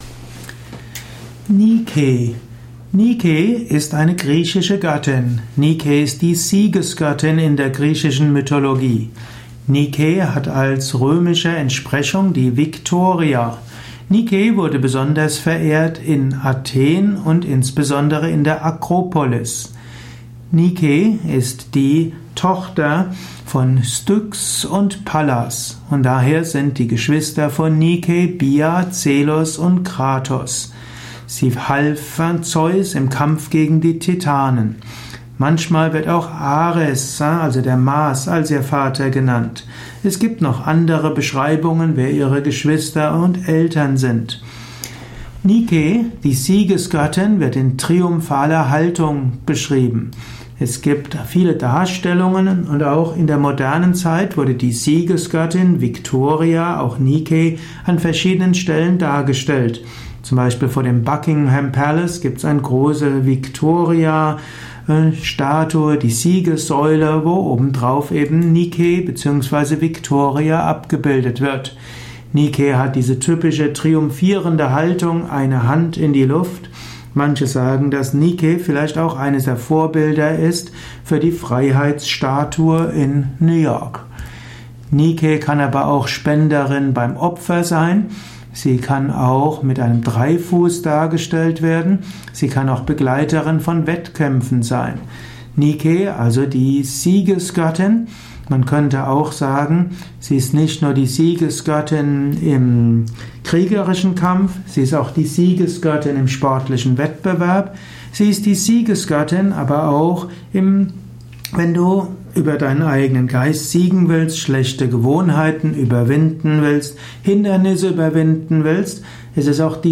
Dies ist die Tonspur eines Videos, zu finden im Yoga Wiki.